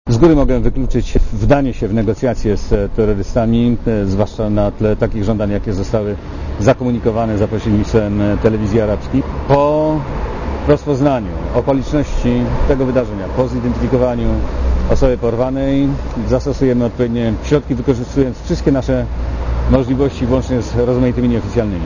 * Mówi minister Włodzimierz Cimoszewicz*